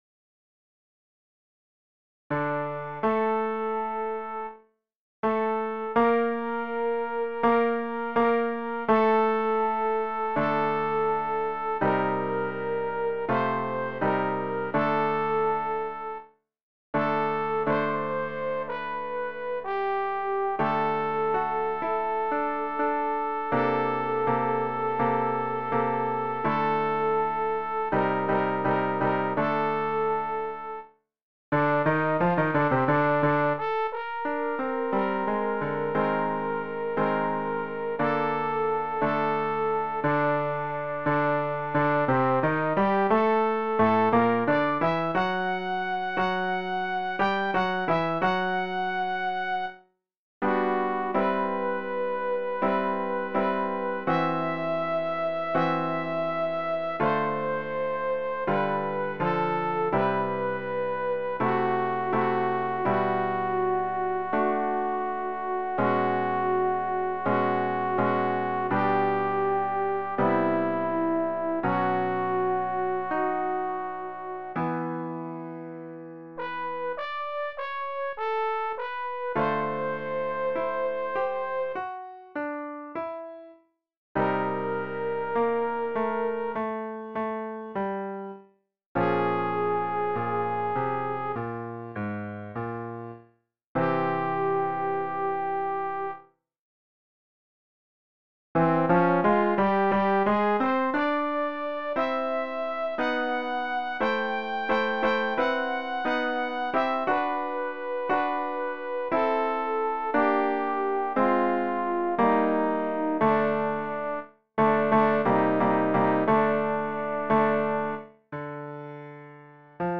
Übehilfen für das Erlernen von Liedern
nanzer-mein-herr-und-mein-gott-sopran.mp3